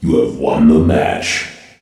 voc_youwin.ogg